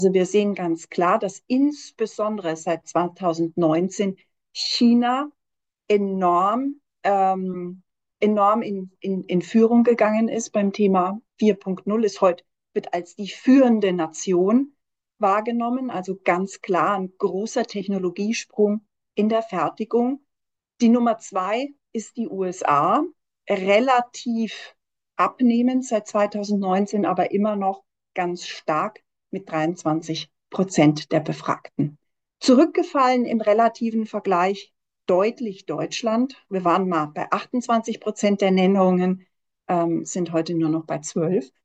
Mitschnitte der Pressekonferenz